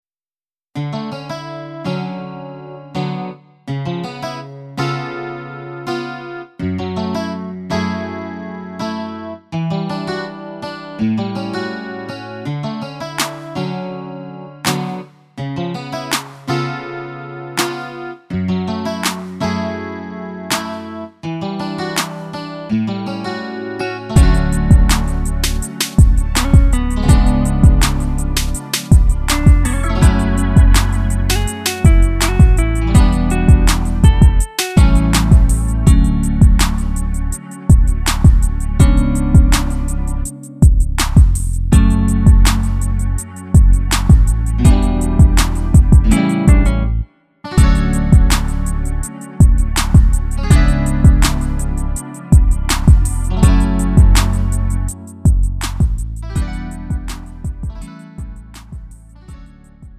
음정 -1키 3:23
장르 가요 구분